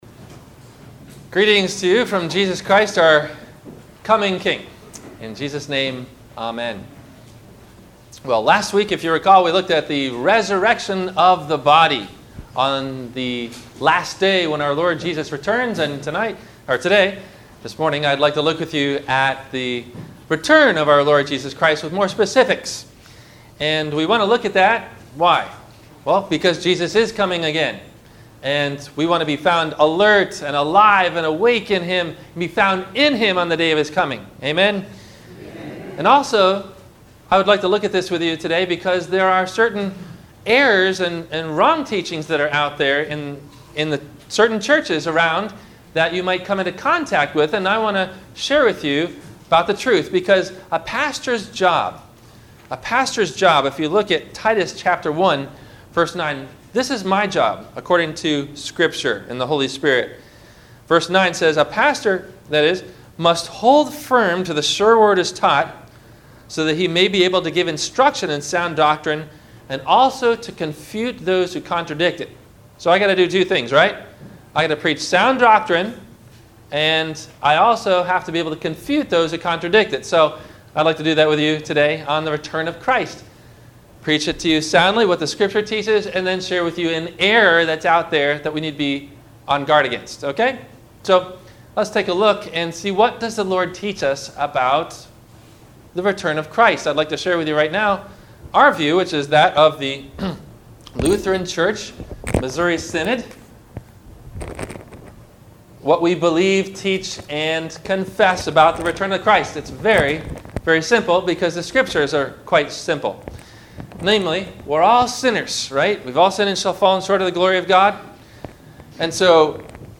The Right and the Wrong View of Christ’s Return – Sermon – April 24 2016